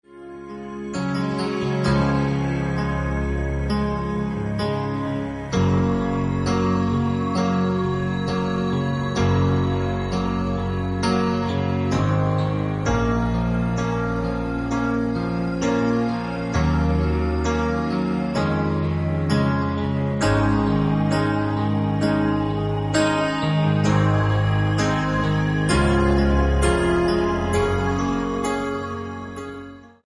MP3 – Original Key – Backing Vocals Like Original
Pop